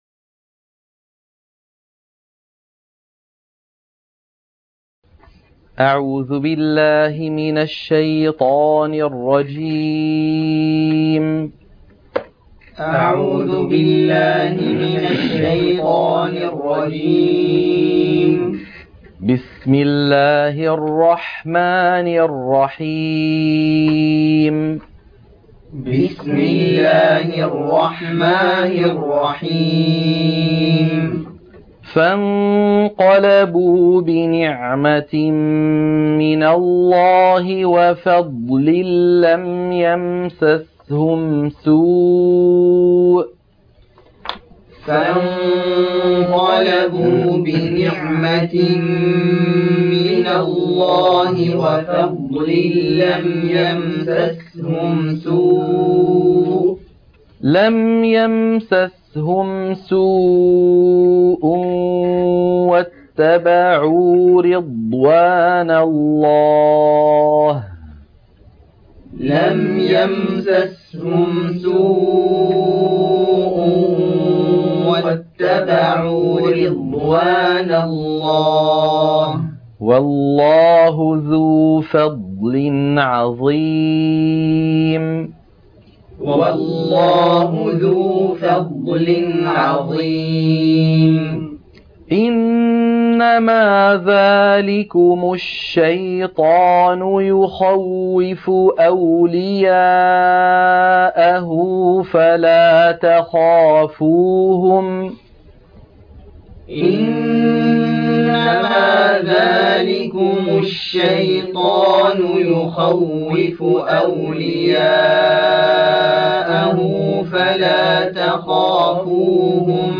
عنوان المادة تلقين سورة آل عمران - الصفحة 73 التلاوة المنهجية